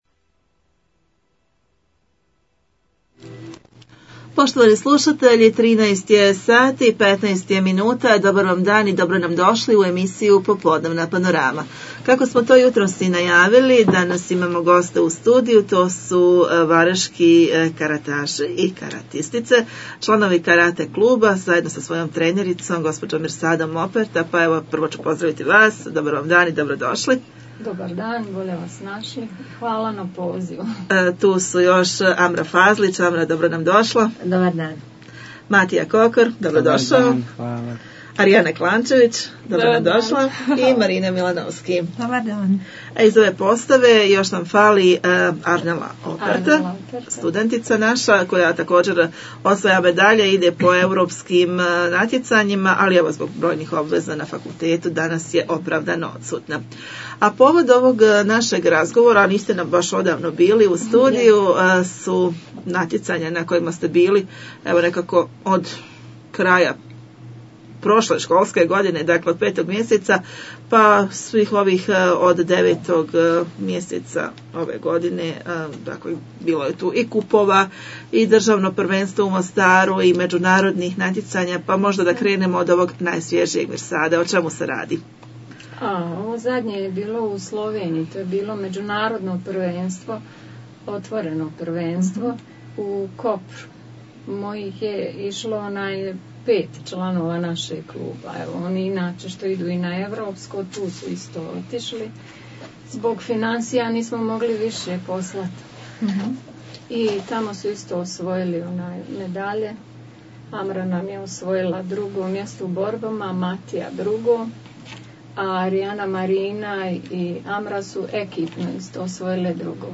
U studiju smo ugostili naše zlatne karataše. Čestitamo na uspjehu, a o narednim i prethodnim aktivnostima poslušajte u nastavku....